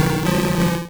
Cri de Kicklee dans Pokémon Rouge et Bleu.